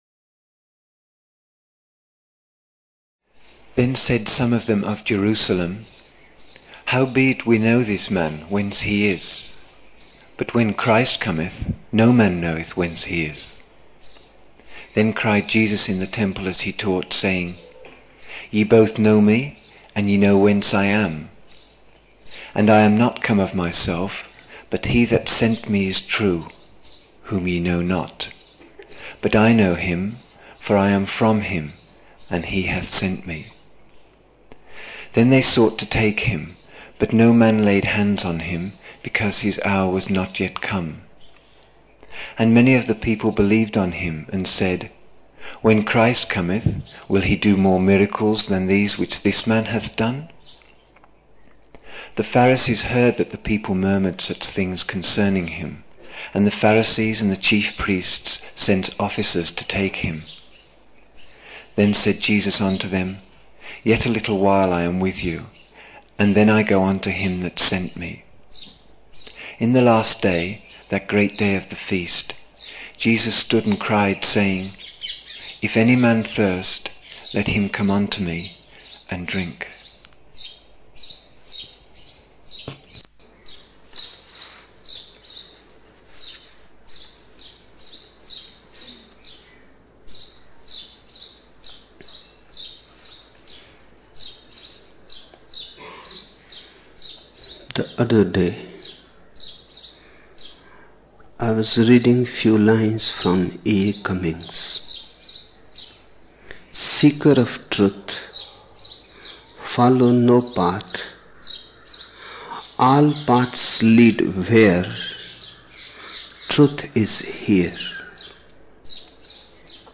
19 December 1975 morning in Buddha Hall, Poona, India